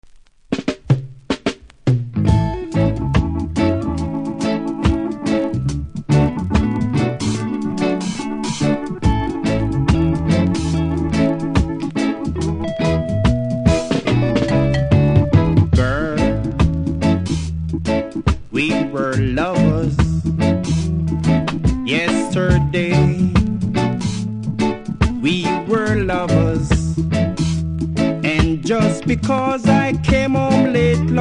REGGAE 70'S
多少うすキズありますが音は良好なので試聴で確認下さい。
良いラブソングでお勧めですがプレス起因の針飛びがあります。
針飛びの場所は最後の方でリピートせずに流れますのでそれでもいい方だけ購入下さい。